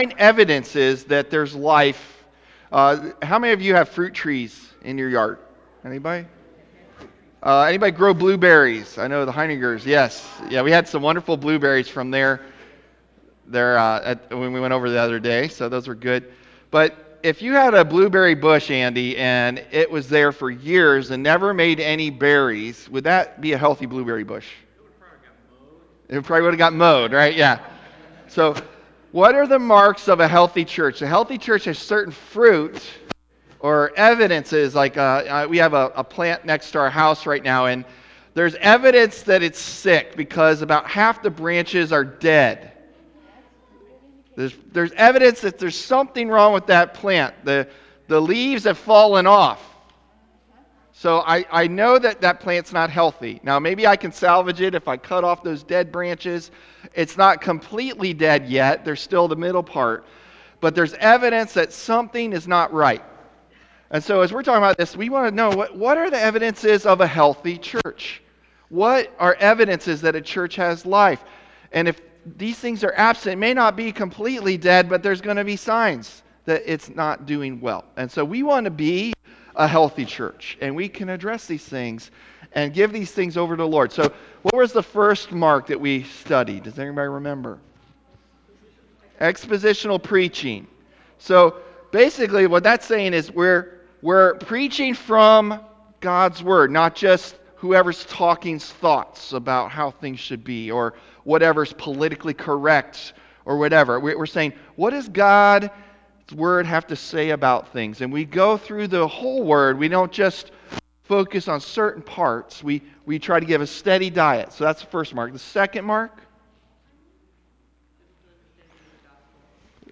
July 9 Sermon | A People For God